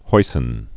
(hoisĭn, hoi-sĭn)